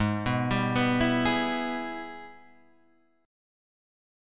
G# Minor Major 7th Sharp 5th